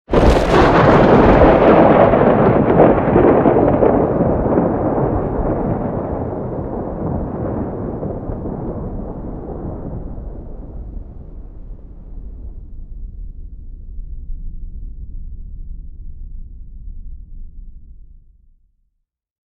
thunder_14.ogg